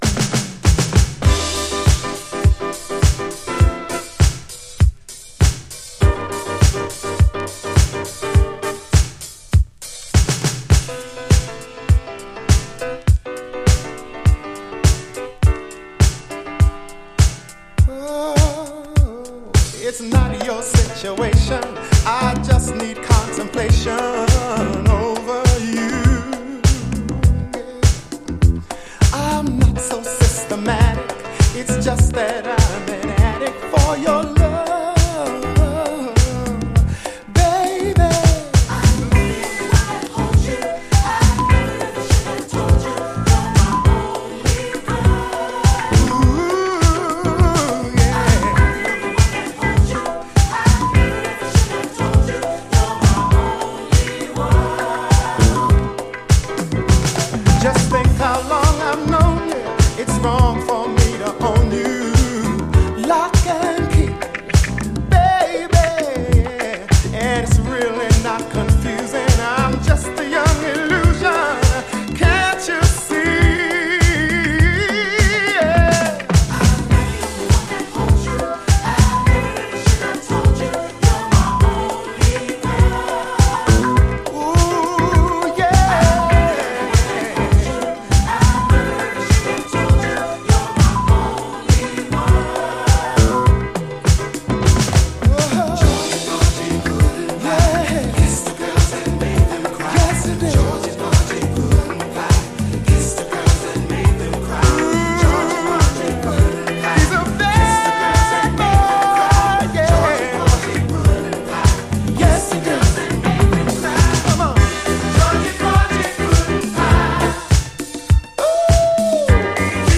SOUL, 70's～ SOUL, DISCO
こちらはボトムを少し強調した82年のREMIXED VERSIONで、B面の同インスト・ヴァージョンが見逃せない！